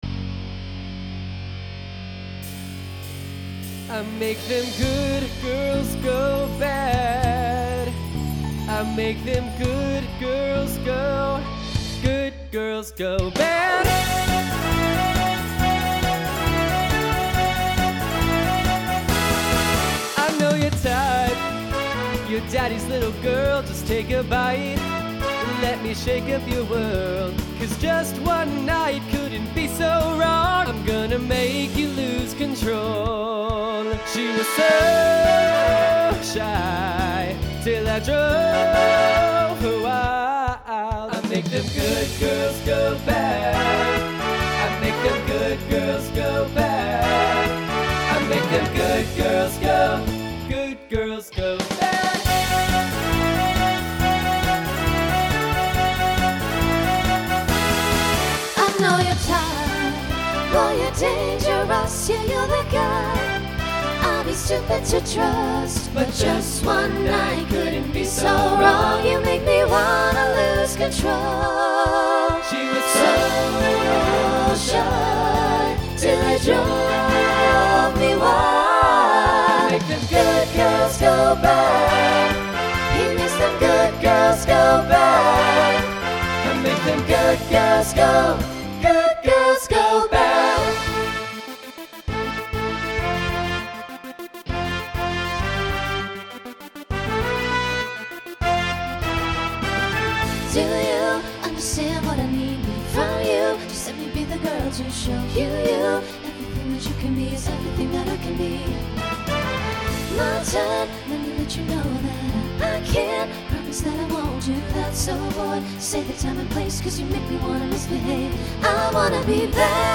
TTB/SSA
Voicing Mixed Instrumental combo Genre Pop/Dance , Rock